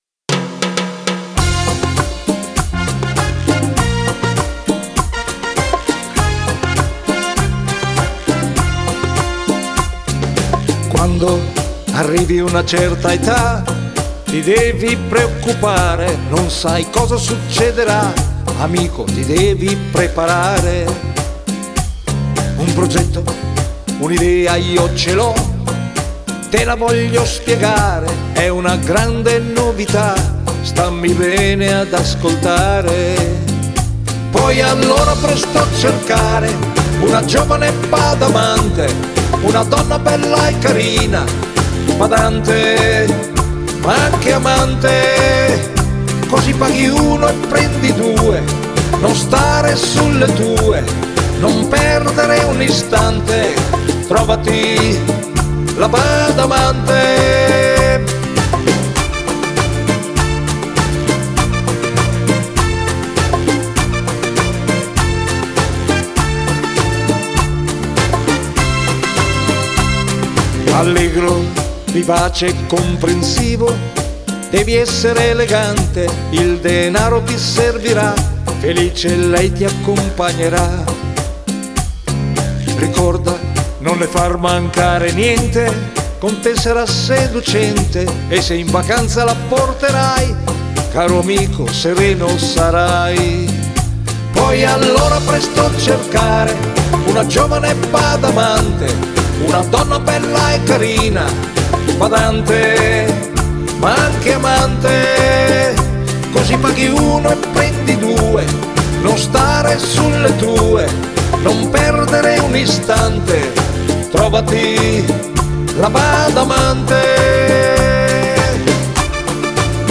Cumbia